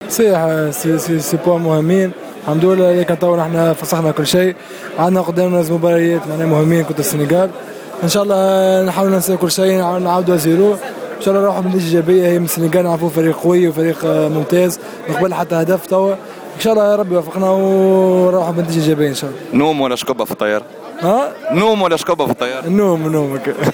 مع بعض لاعبي المنتخب التونسي في مطار المنستير قبل انطلاق الرحلة التي ستستغرق خمسة ساعات.